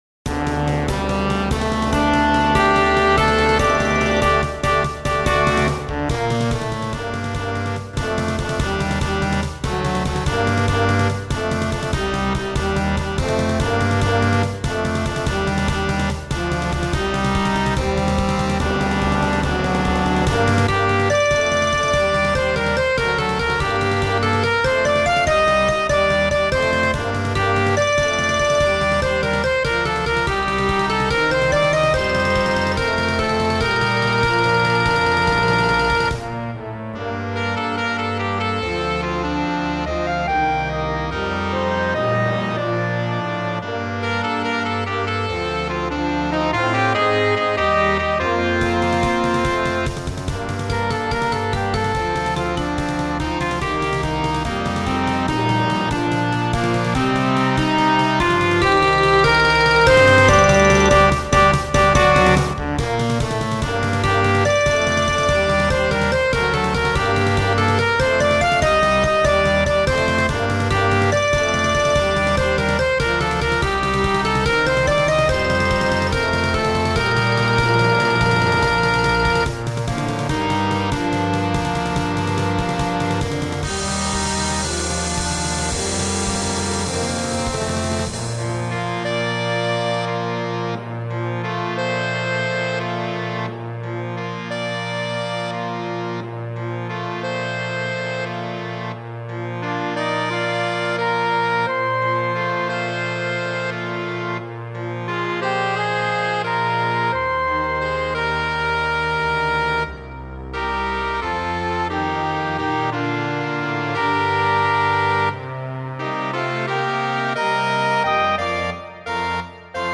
Gattung: Flexible Besetzung
Besetzung: Blasorchester
Dieses temperamentvolle Werk